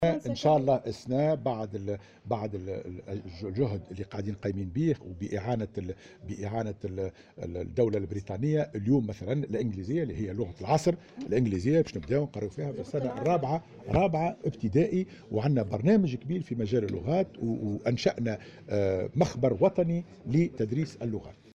وأضاف في تصريح اليوم لمراسلة "الجوهرة أف أم" على هامش انطلاق الندوة الوطنية حول التربية، أنه سيتم انطلاقا من السنة الدراسية القادمة تدريس اللغة الانقليزية لتلاميذ السنة الرابعة ابتدائي.